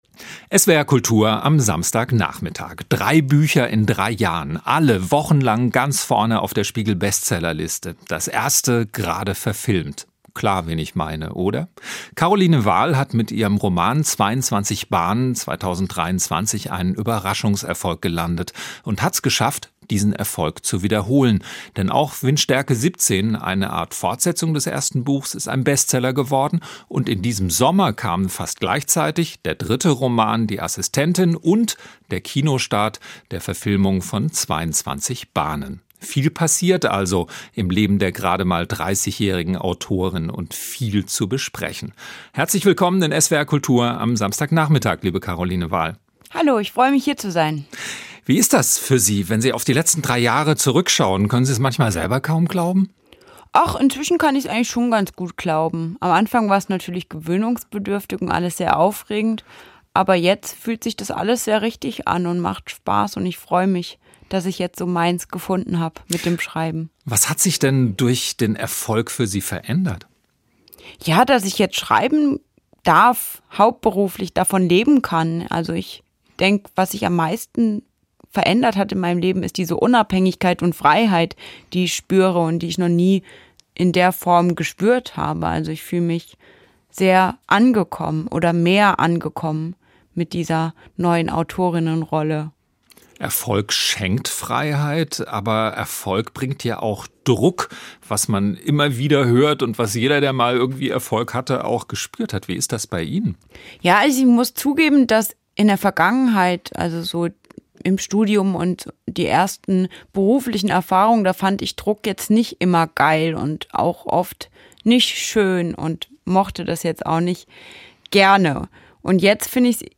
caroline-wahl-interview-bestseller-autorin-in-kritik-dankbar-kaempferisch.mp3